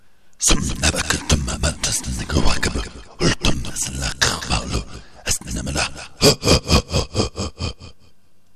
Parfois au cours d'un combat, ils invectivent leurs adversaires avec une voix tonitruante qui bourdonne dans les oreilles.
En effet, leur grammaire semble constituée de grognements, rires et verbes (de 2 syllabes maximum) suivis d'adjectifs.
troll_guerrier.mp3